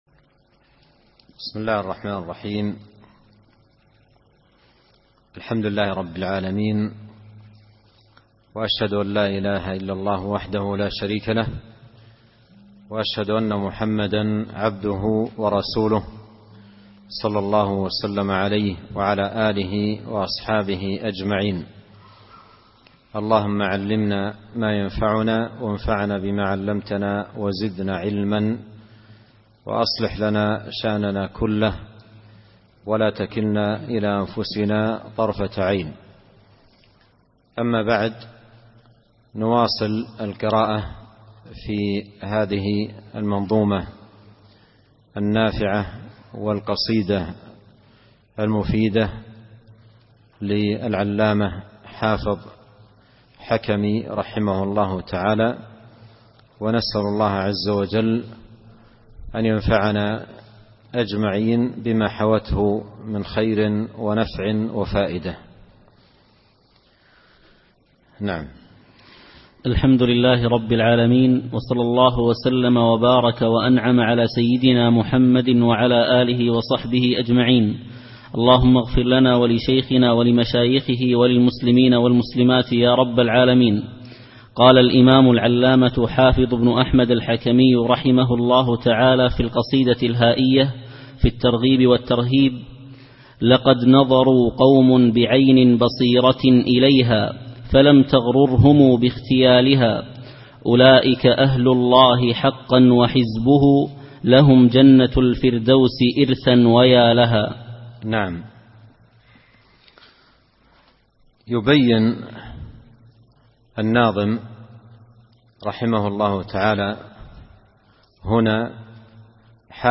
الدرس الثاني